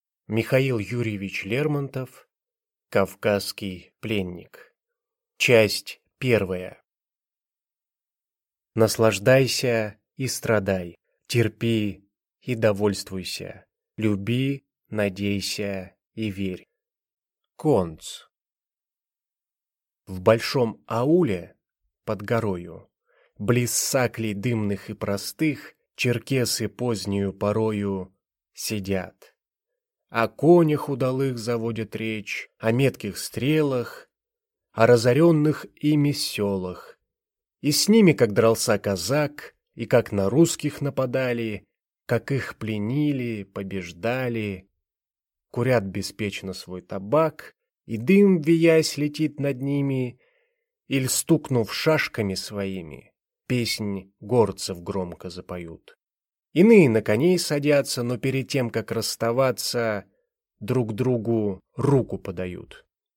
Аудиокнига Кавказский пленник | Библиотека аудиокниг